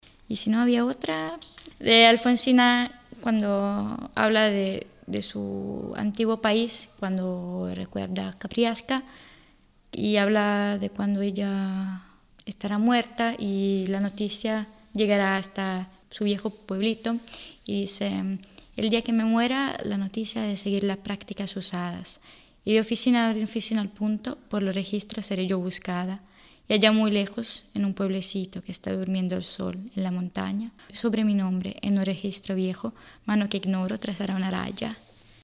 Poema 'Borrada'